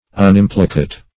Search Result for " unimplicate" : The Collaborative International Dictionary of English v.0.48: Unimplicate \Un*im"pli*cate\, a. Not implicated.
unimplicate.mp3